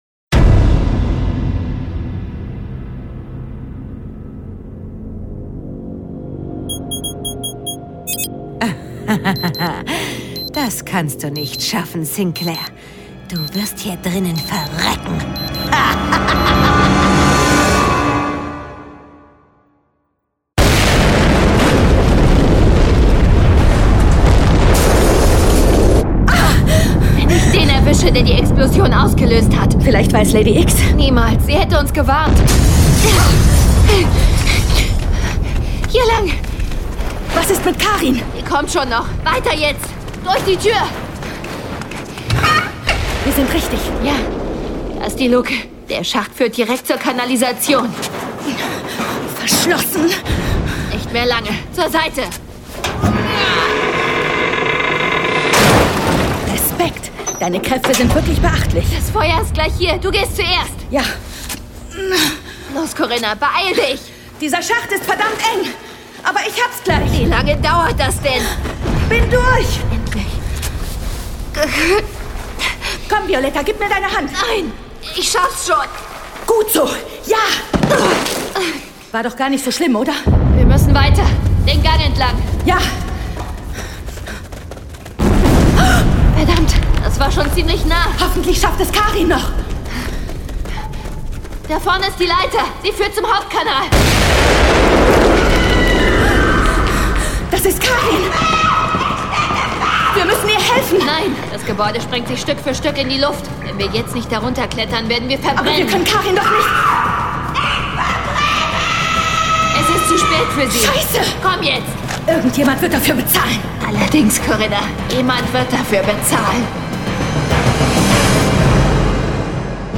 John Sinclair - Folge 54 Ein schwarzer Tag in meinem Leben. Hörspiel.